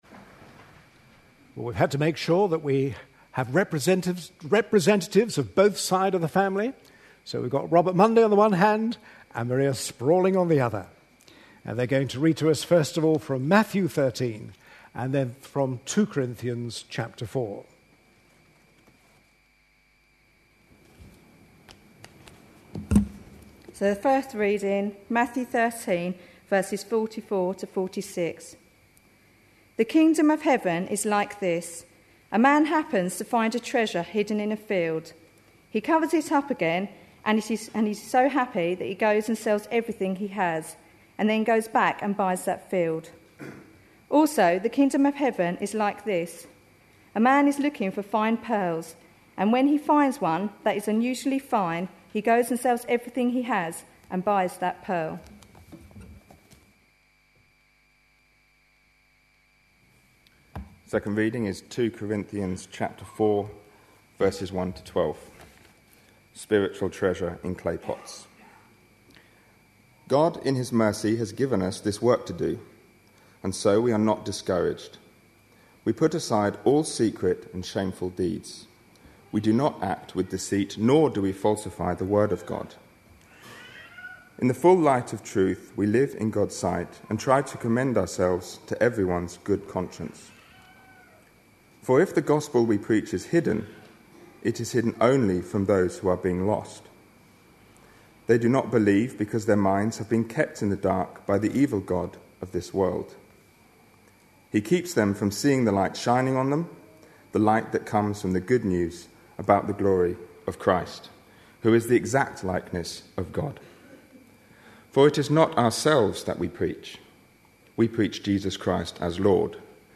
A sermon preached on 3rd July, 2011, as part of our God At Work In Our Lives. series.